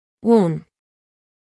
Phoneme_(Umshk)_(Uun)_(Female).mp3